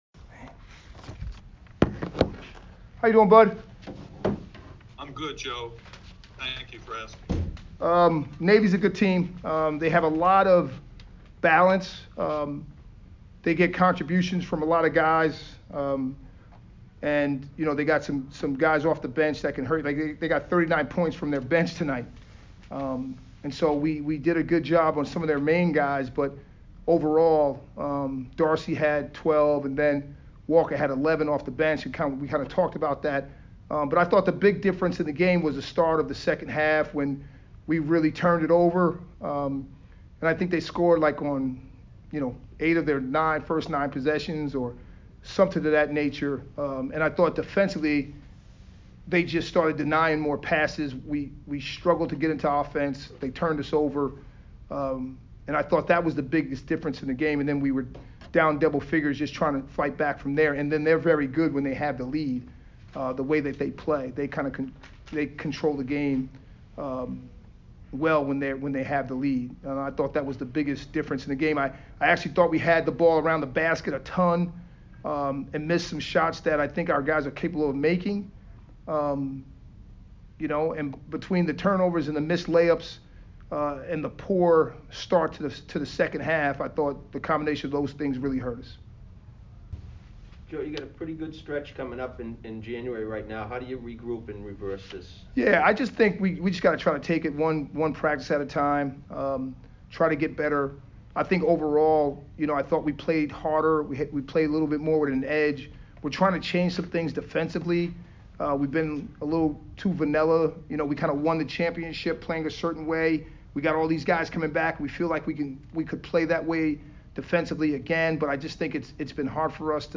Navy MBB Press Conference